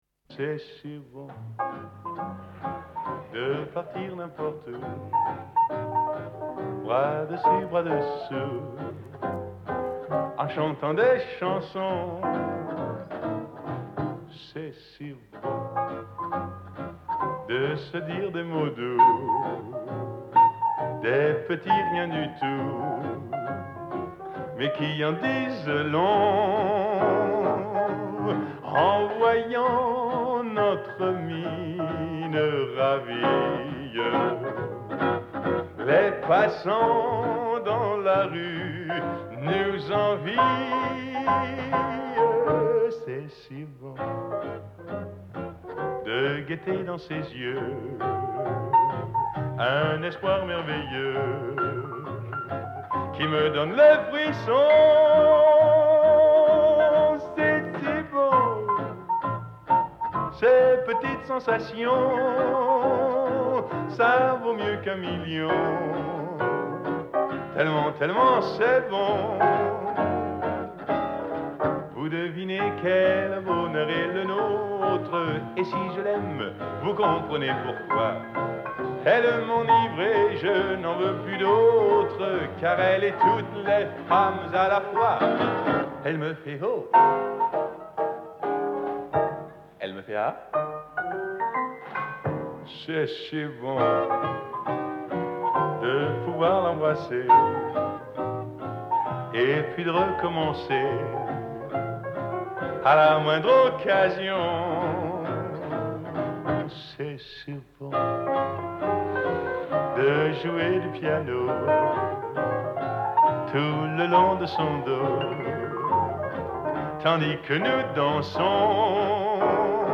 темп медленее, из инструментов слышно только рояль.